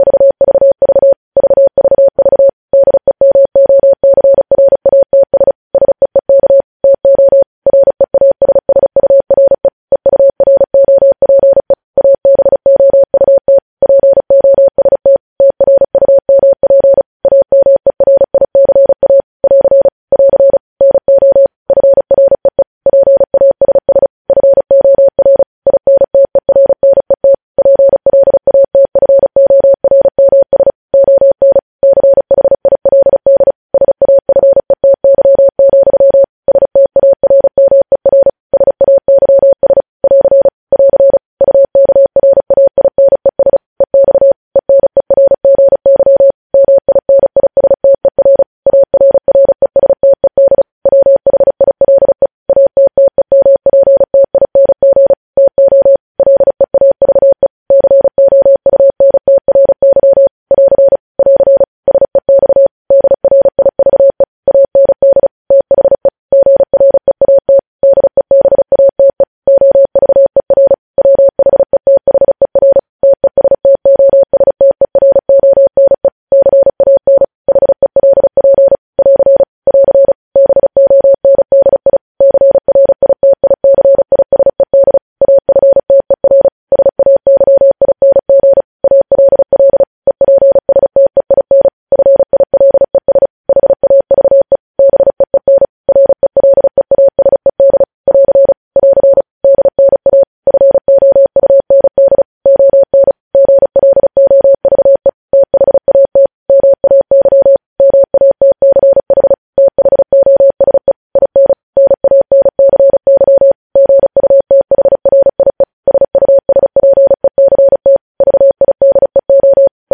News Headlines in Morse Code at 35 WPM
News Headlines in Morse code, updated daily